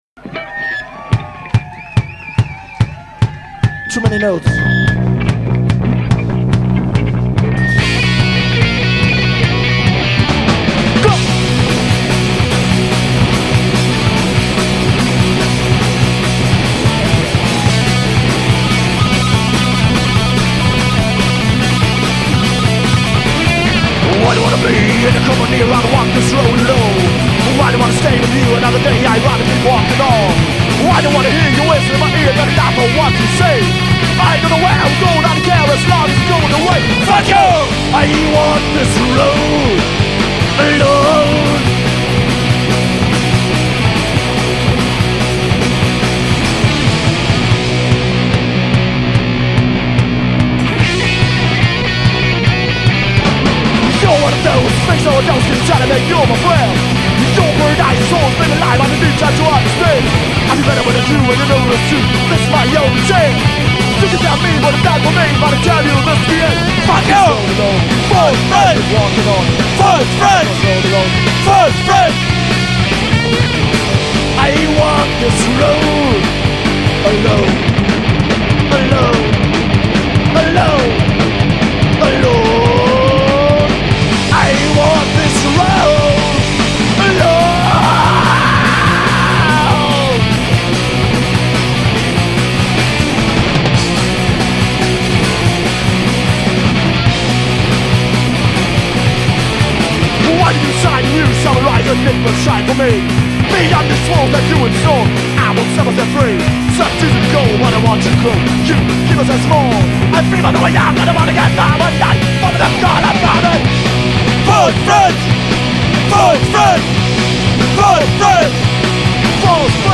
Live in der KVU: Mitschnitte vom Konzert am 11.12.2004